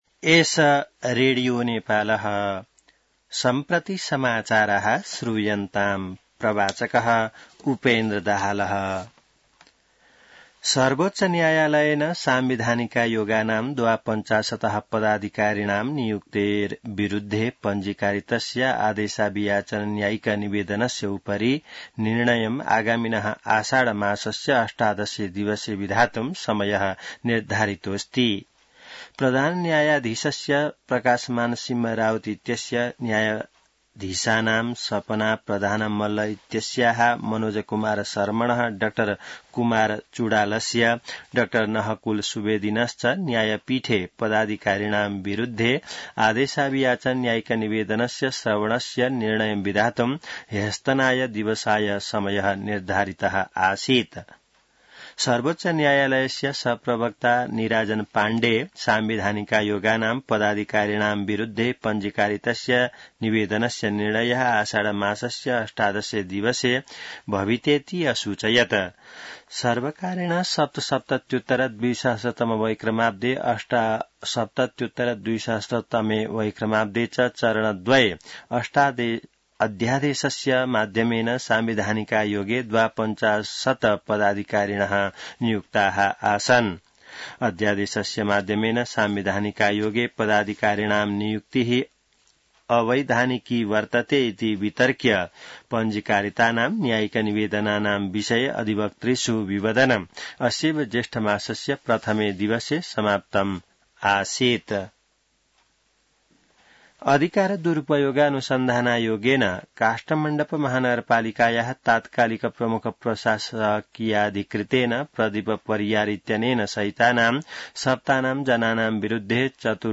संस्कृत समाचार : २९ जेठ , २०८२